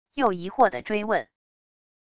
又疑惑的追问wav音频